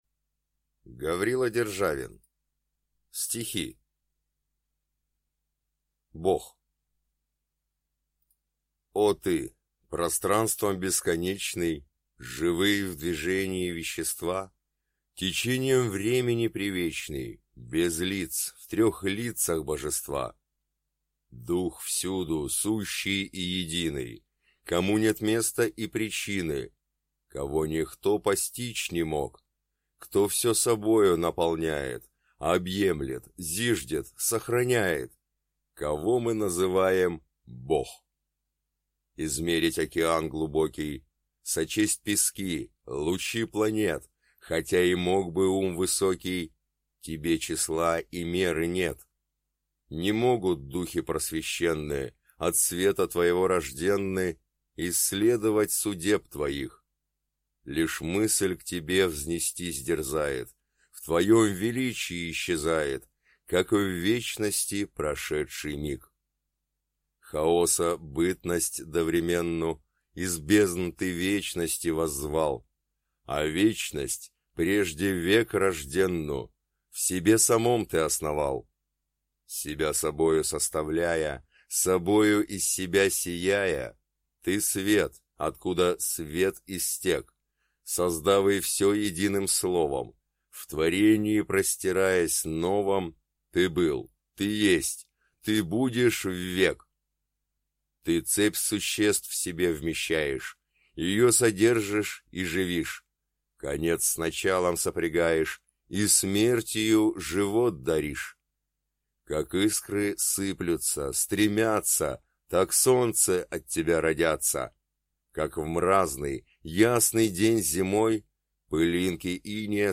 Аудиокнига Стихи | Библиотека аудиокниг